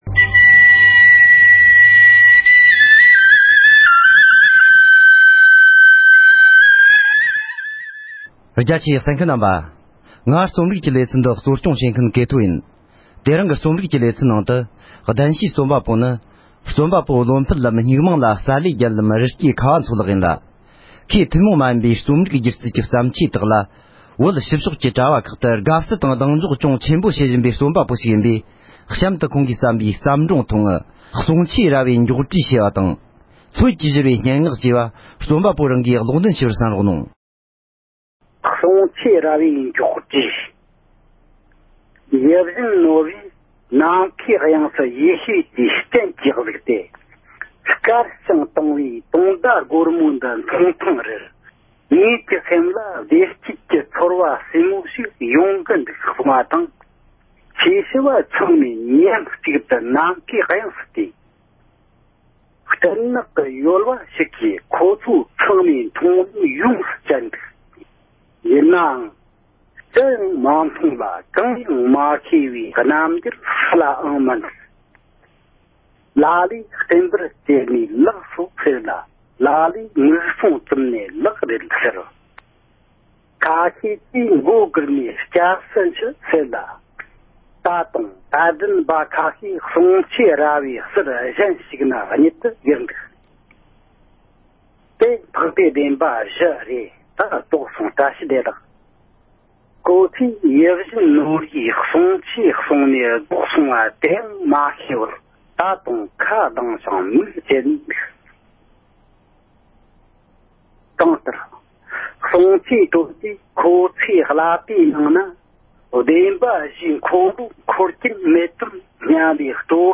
རྩོམ་པ་པོ་བློ་འཕེལ་ལགས་ཀྱི་བརྩམས་ཆོས་ཁག་གཉིས་ཀློག་འདོན་བྱས་པ།